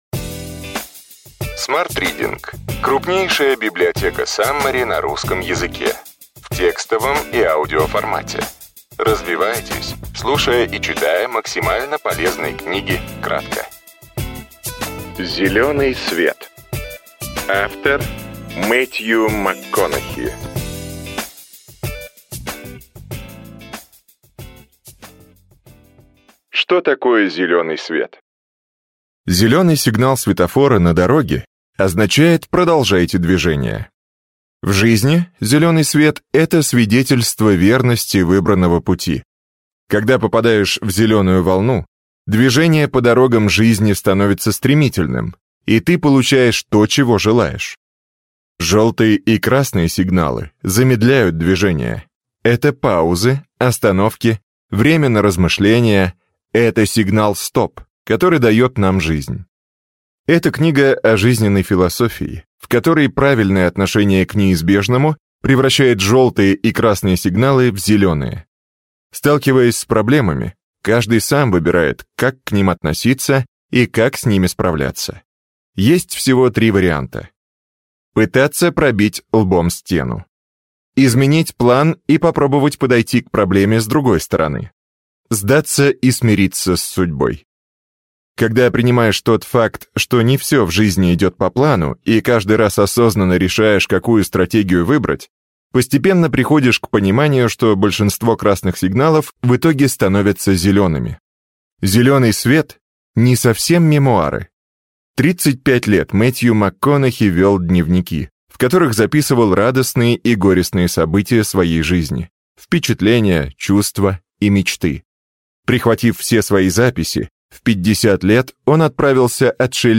Аудиокнига Зеленый свет. Мэттью Макконахи. Саммари | Библиотека аудиокниг
Прослушать и бесплатно скачать фрагмент аудиокниги